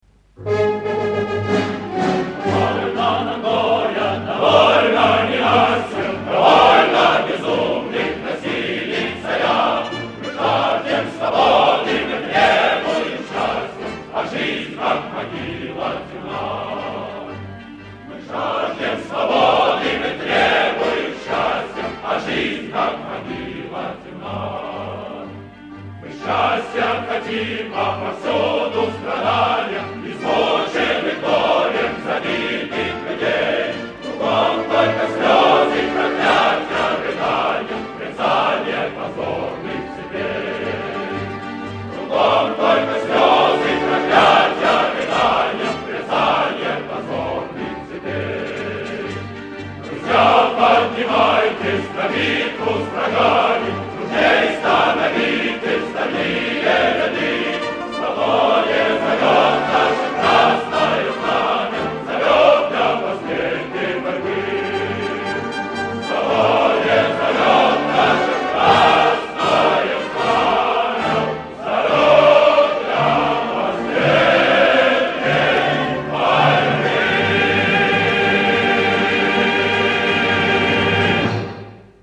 Хора из оперы